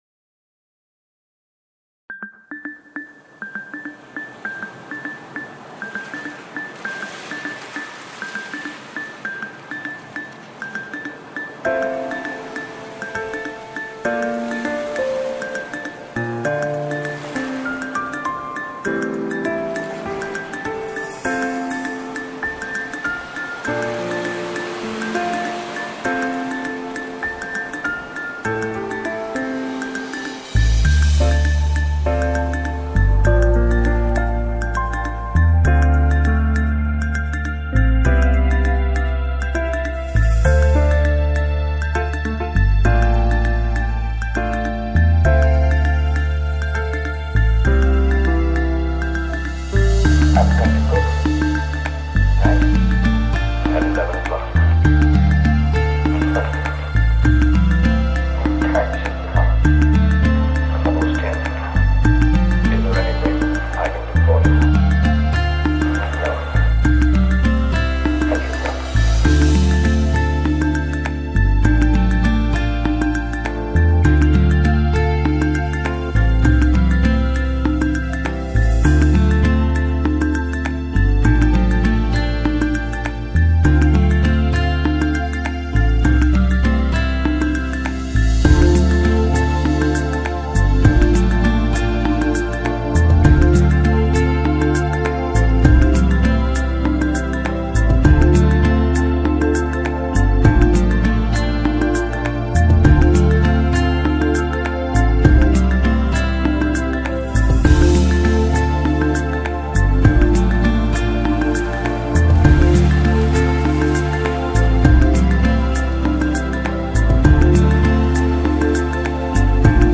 Style: Chillout - Lo-Fi